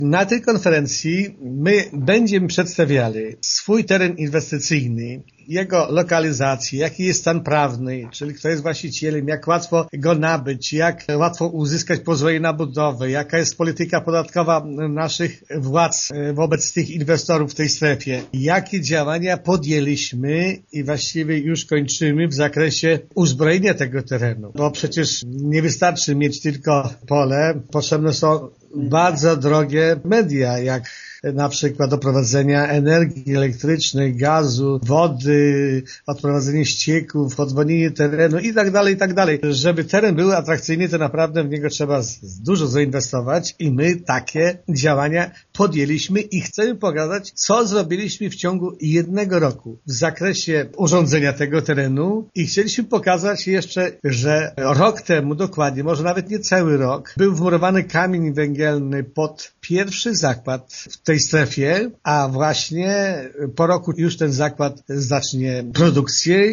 „Mamy atrakcyjny teren, ale bez odpowiedniej reklamy, nawet bardzo dobry towar trudno jest sprzedać” - mówi zastępca wójta Wiktor Osik: